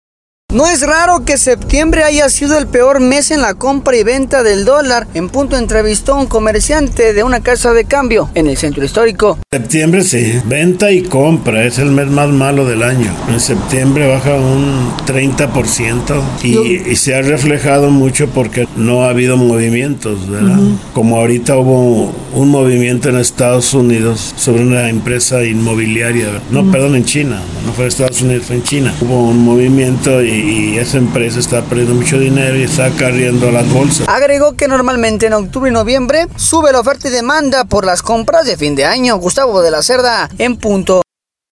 No es raro que septiembre haya sido el peor mes en la compra y venta del dólar, En Punto, entrevistó al comerciante de una casa de cambio, en el Centro Histórico.